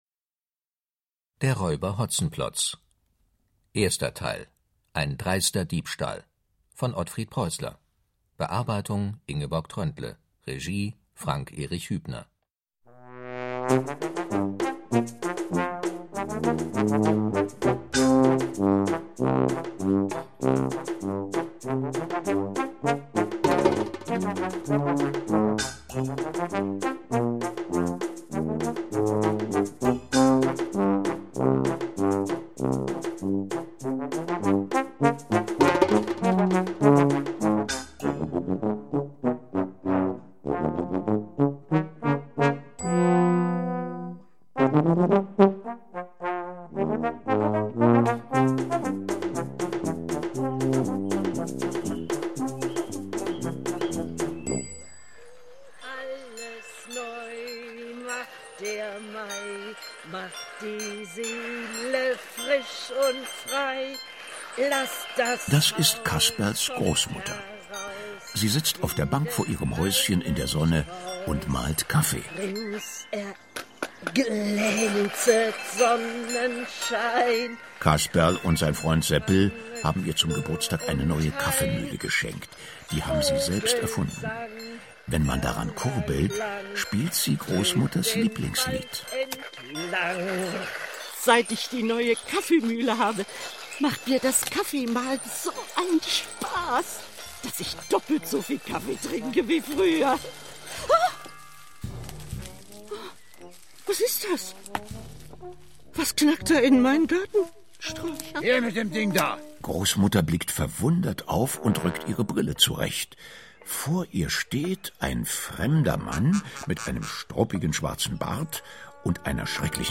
Hörspiele (6 CDs)
brummigen Michael Mendl als Hotzenplotz.